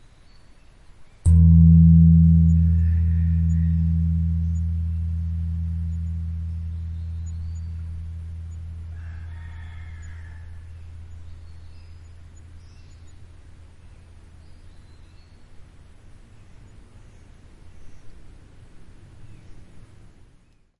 寺庙里的宫(媒)体
描述：龚记录在泰国清迈（Doi Suthep寺）。
Tag: 贝尔 打击乐 寺庙 泰国 清迈 土井 佛教 清迈 素贴